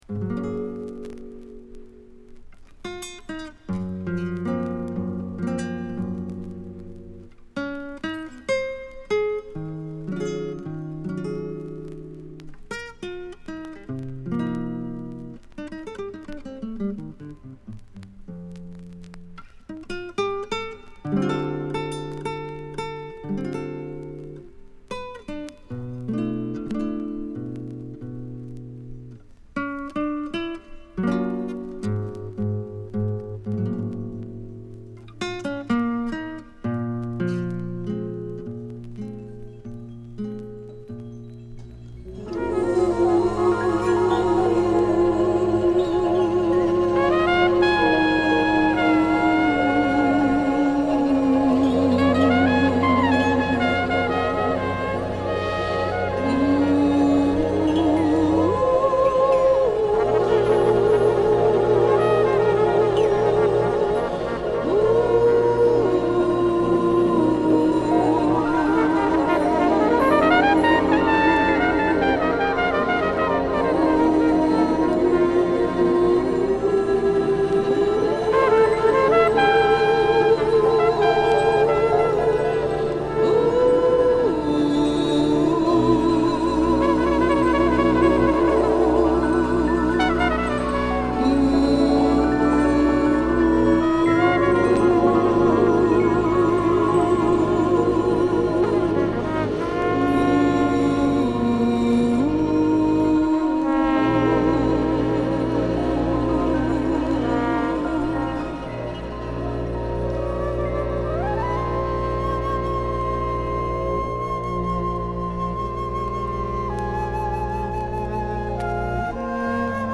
フィラデルフィア出身のジャズドラマー